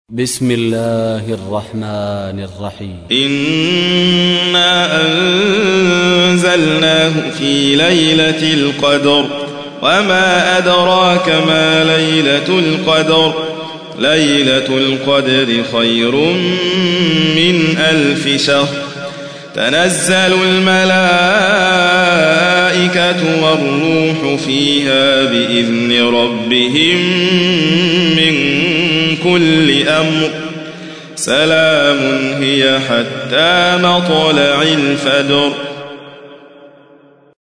تحميل : 97. سورة القدر / القارئ حاتم فريد الواعر / القرآن الكريم / موقع يا حسين